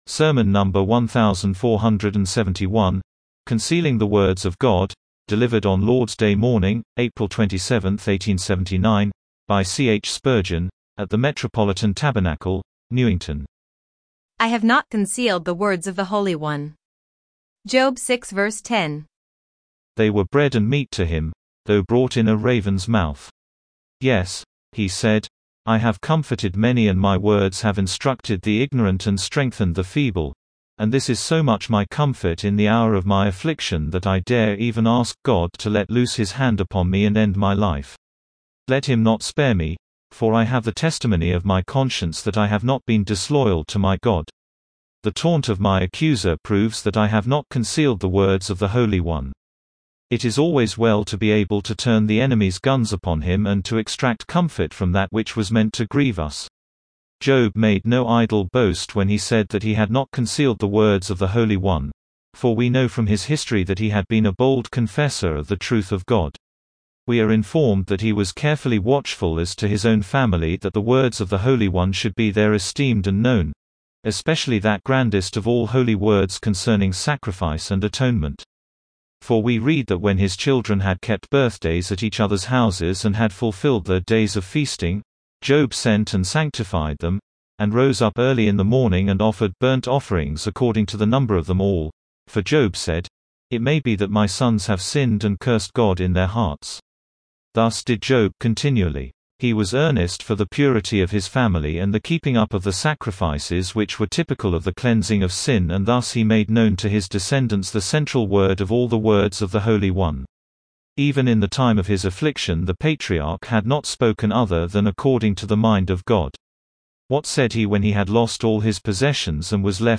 Sermon number 1,471, CONCEALING THE WORDS OF GOD